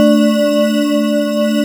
PADY CHORD05.WAV